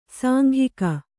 ♪ sānghika